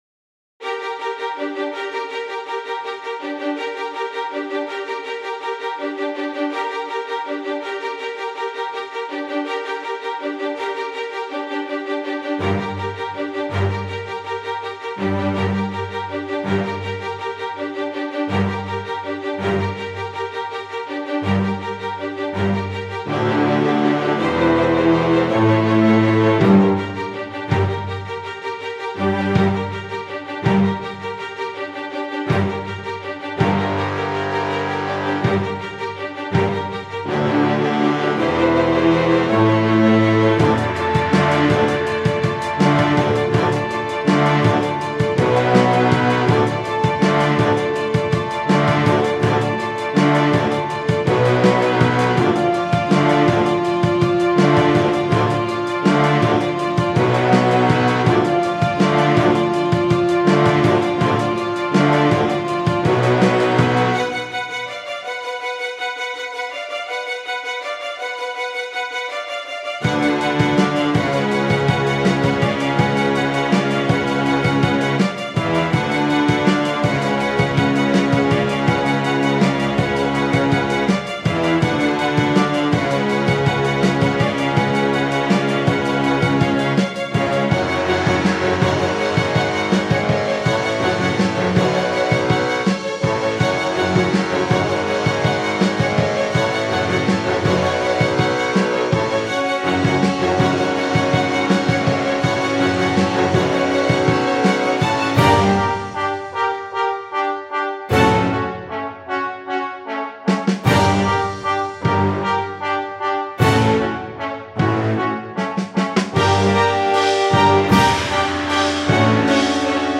塔玛小鼓套装" 开放10
描述：打我的老TAMA小鼓时没有静音。速度级别为满级。
声道立体声